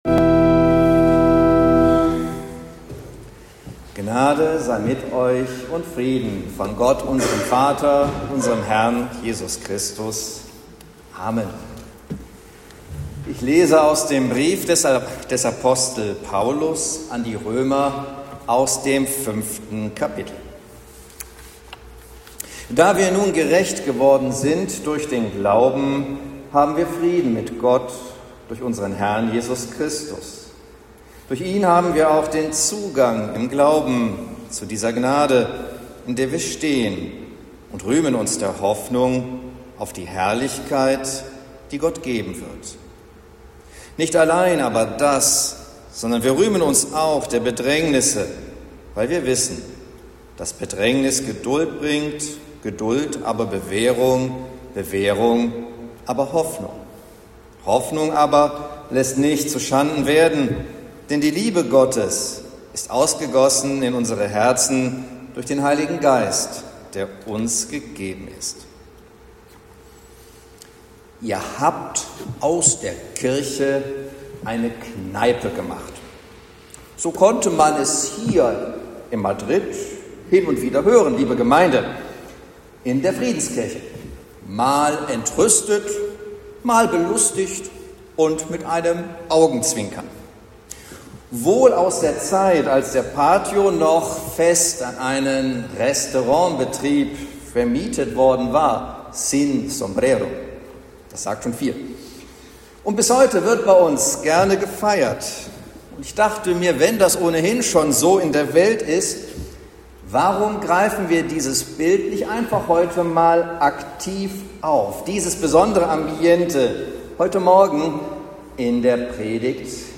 Predigt zu Reminiszere